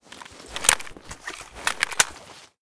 ak105_reload.ogg